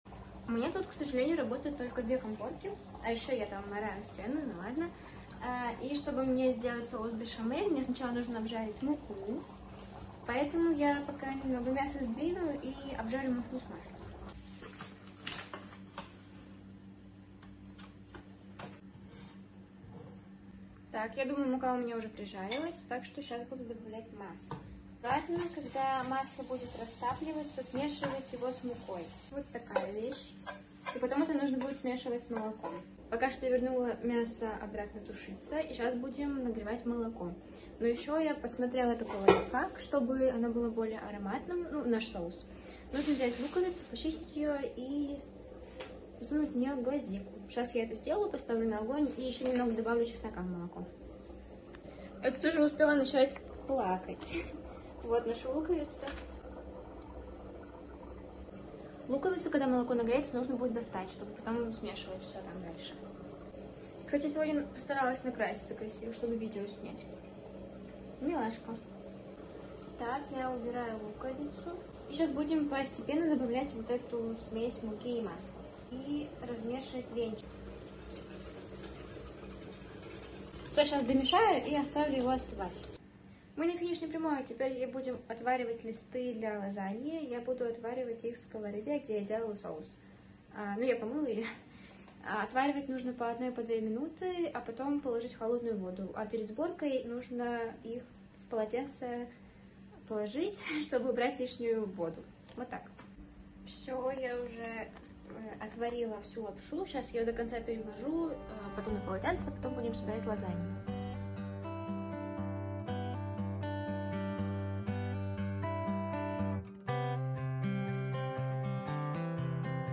cooking lasagna sound effects free download